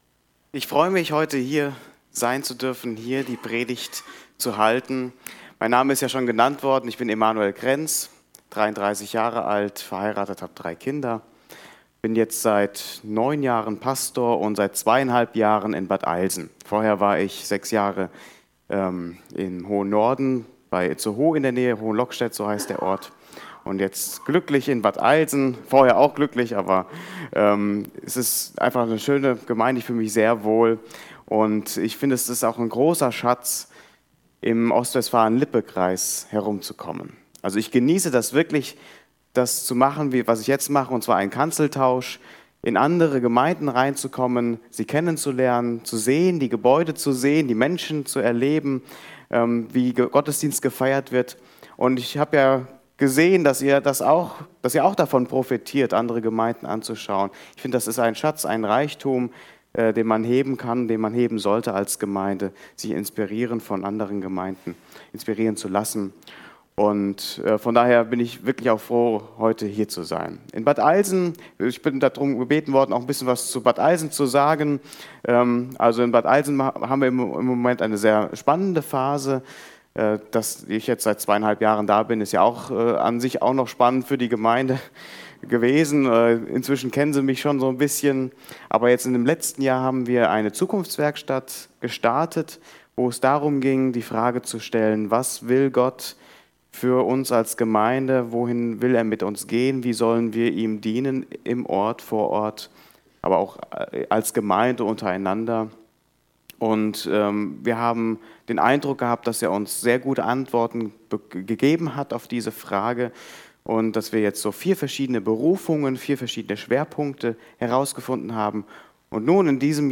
Bible Text: Kolosser 2,1-15 | Prediger/in